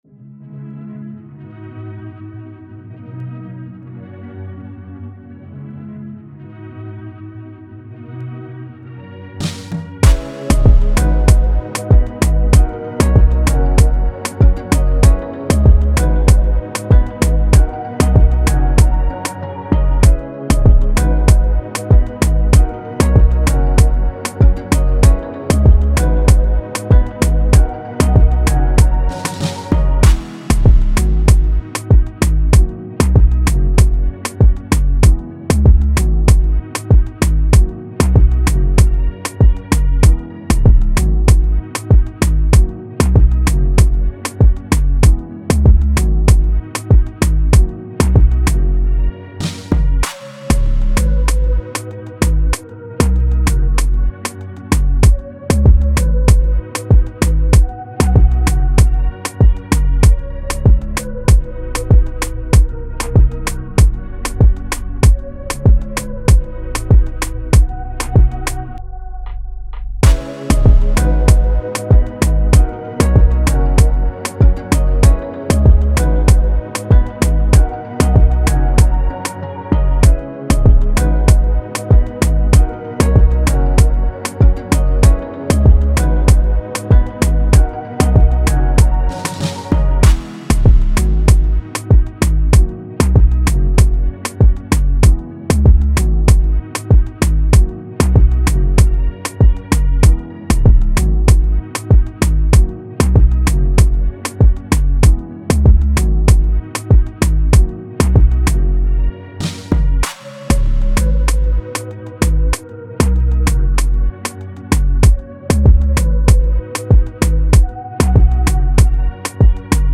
Afrobeat
g#Minor